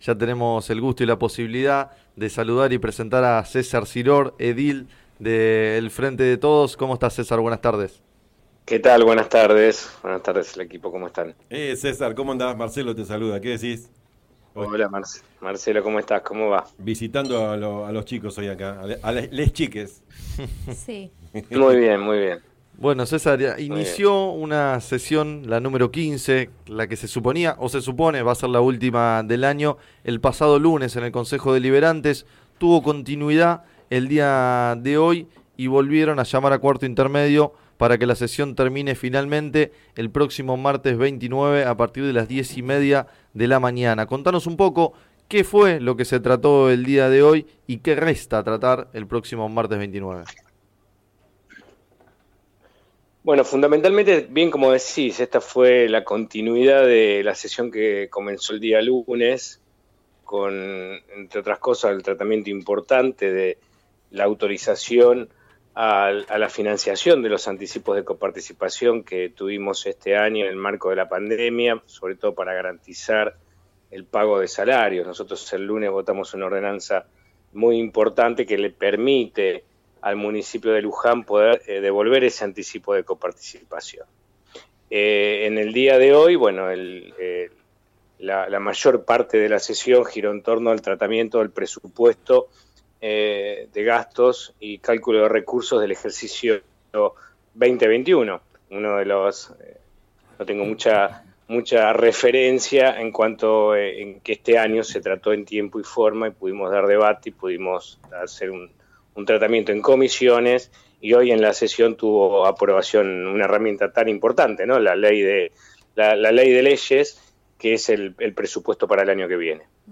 En declaraciones al programa “Sobre las cartas la mesa” de FM Líder 97.7, el concejal César Siror, del interbloque del Frente de Todos, explicó que el Presupuesto “apunta a sostener el sistema de Salud y Desarrollo Humano y garantizar el pago en tiempo y forma de los salarios de los trabajadores municipales”.